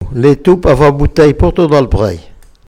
Enquête Arexcpo en Vendée
locutions vernaculaires